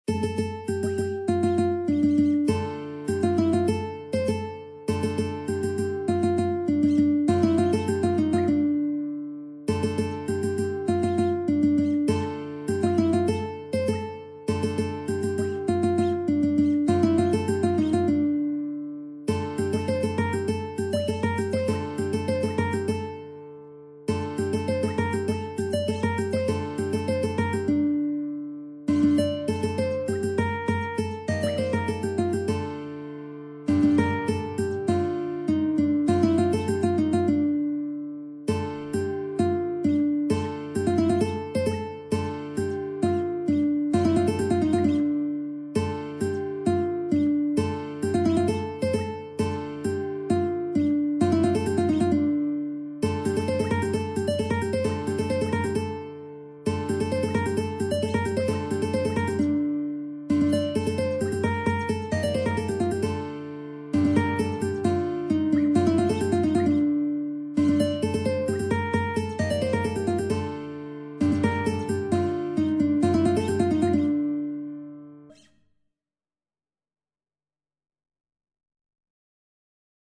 نت ملودی به همراه تبلچر و آکورد